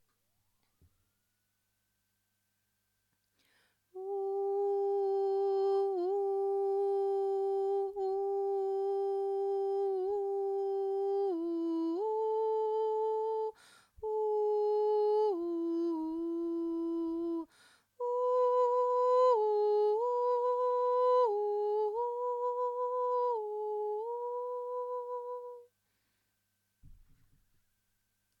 Télécharger la tendresse suite basse
ob_b05ca3_la-tendresse-suite-basse.mp3